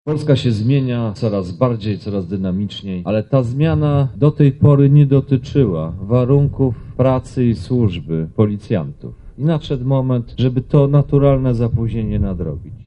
Dziś odbyło się oficjalne otwarcie.
Ten komisariat jest przykładem właściwego współdziałania między władzami i społecznością miasta a policją – wyjaśnia Bartłomiej Sienkiewicz, Minister Spraw Wewnętrznych